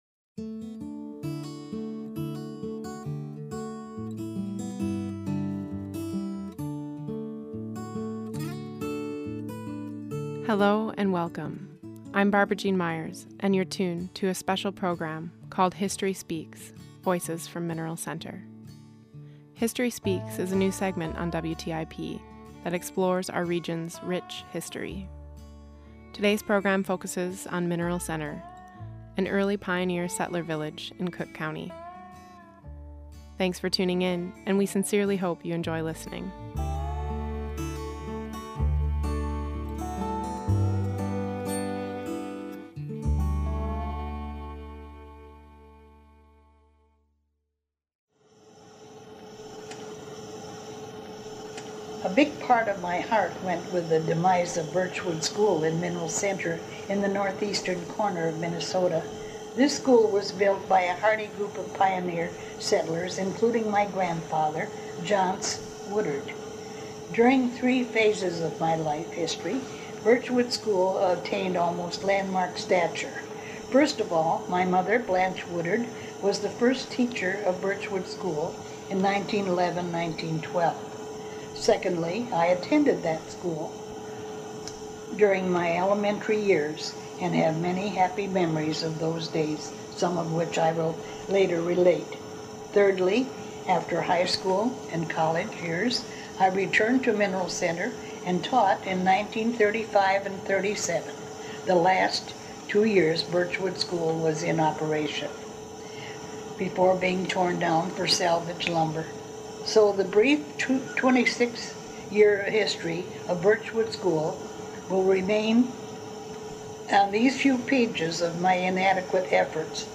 In this special remembrance, you’ll hear the voices of folks who weathered the storm that marks our landscape to this day. Program: History Speaks